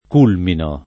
culmino [ k 2 lmino ]